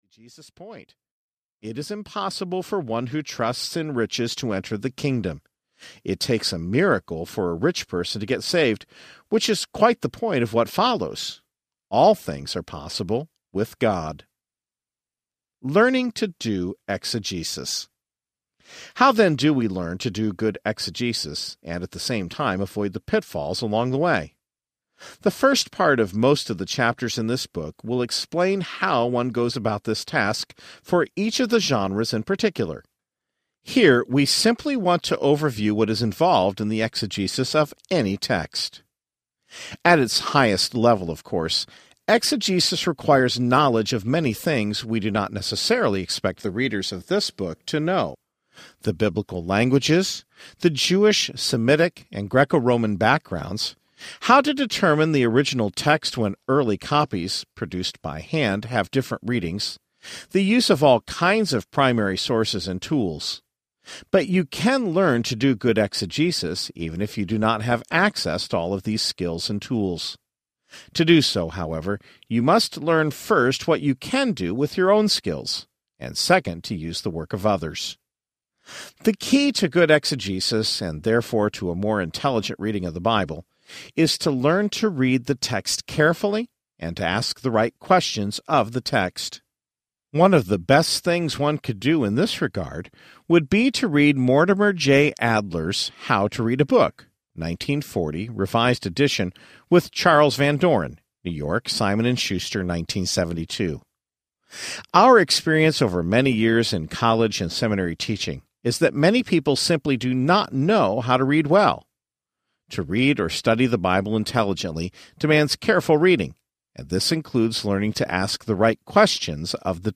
How to Read the Bible for All It’s Worth Audiobook
Narrator
10.75 Hrs. – Unabridged